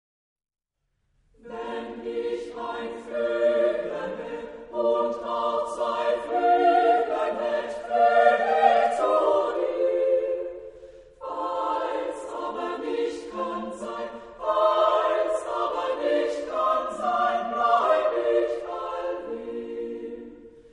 Epoque: 19th century
Type of Choir: women